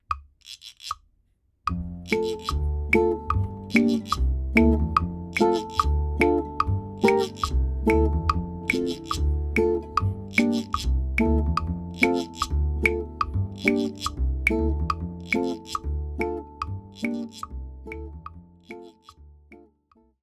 On the recording, I strum on the second half the beat, producing a boom-chuck rhythm.
The bass player is the "boom" whereas 'ukulele is the "chuck." Here's the sound of the Boom-Chuck Strum (melody omitted):
ʻukulele